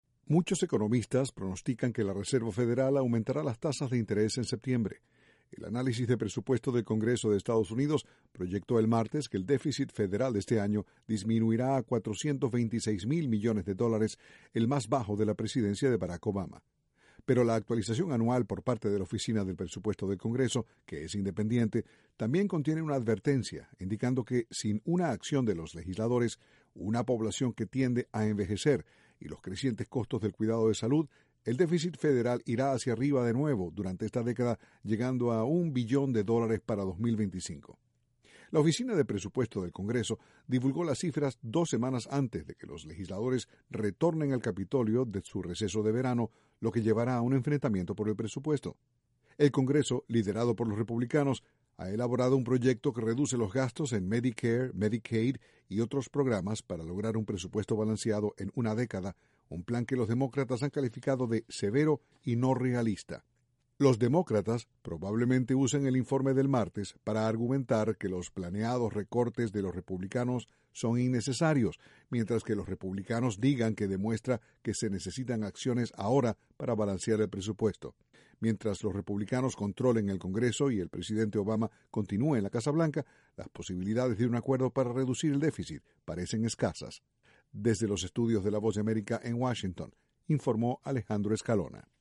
Noticias de economía de Estados Unidos (déficit presupuestario)